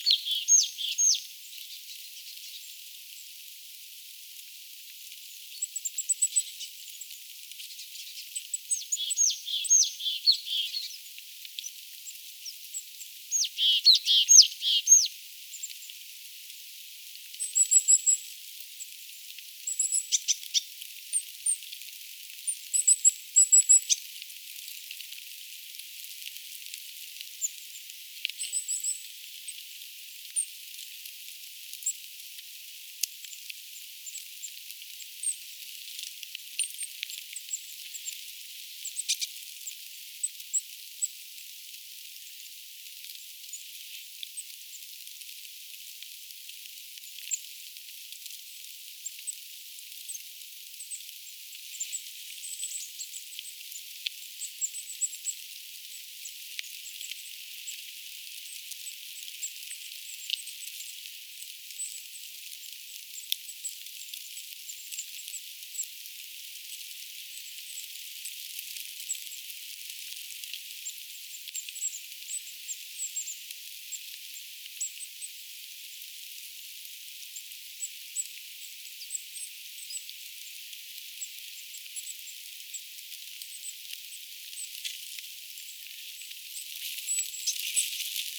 hömötiaislintu laulaa,
kuusitiaisen ääntelyä
homotiaislintu_laulaa_kuusitiaisen_aantelya.mp3